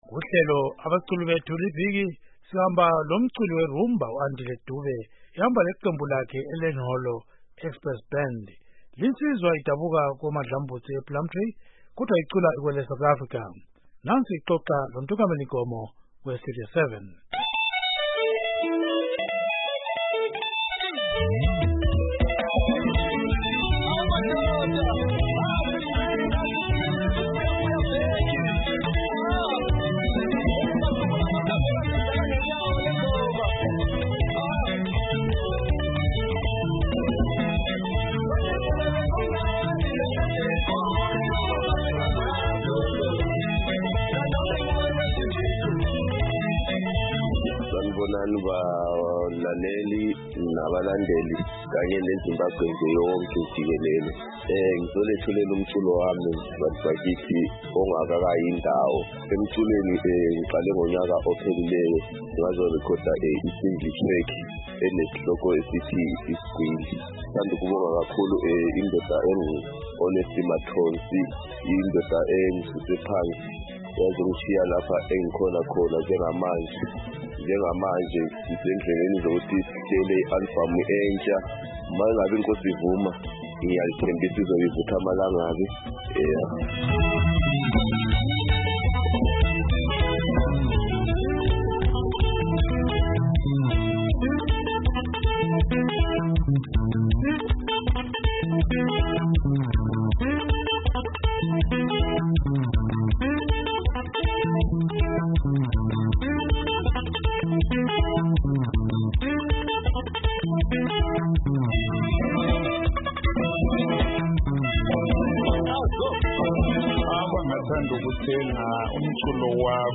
Linsizwa idabuka koMadlambudzi, ePlumtree, kodwa icula ikweleSouth Africa. Nansi ixoxa